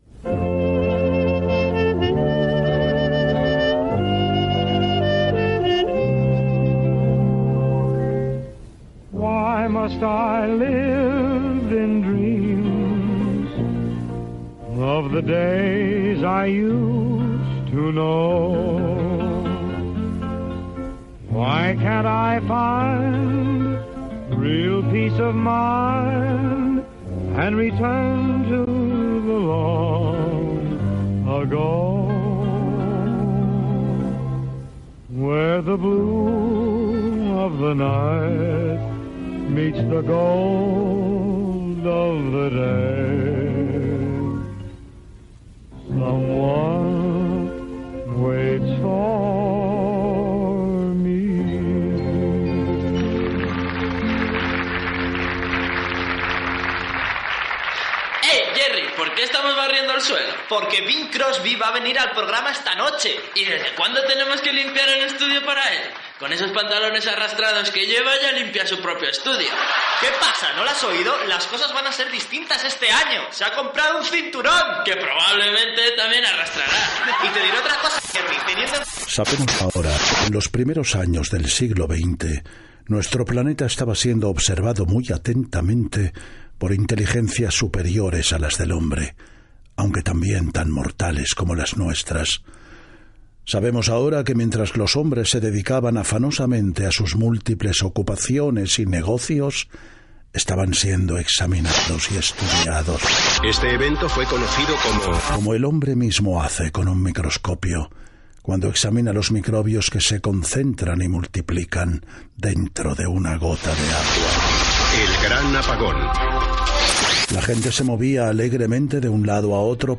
9ae248db491456da3906aa0802adb241d8c1f0f1.mp3 Títol Cadena SER Emissora Ràdio Barcelona Cadena SER Titularitat Privada estatal Nom programa El año de Podium Descripció Fragments de diversos programes, enquesta sobre què és un pòdcast, definció d'aquest.
Gènere radiofònic Divulgació